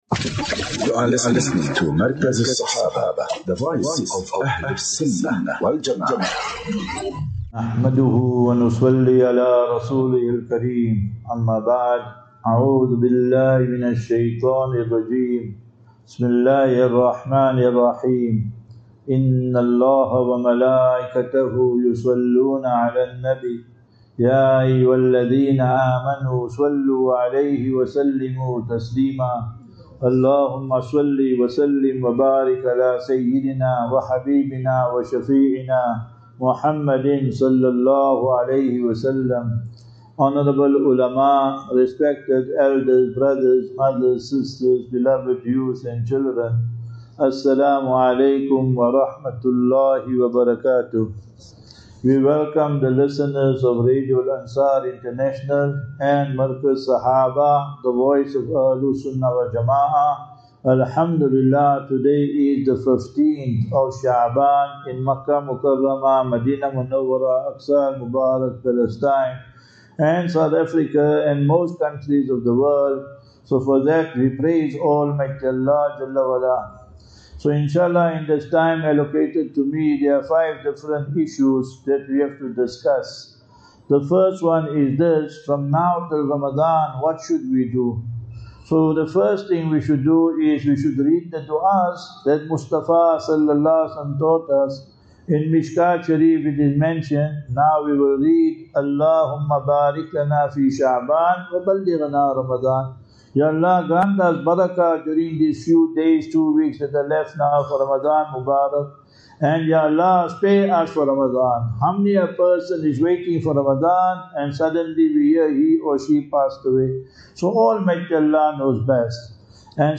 14 Feb 14 February 25 - Jumu;ah Lecture - MASJIDUS SAWLEHEEN (PMB)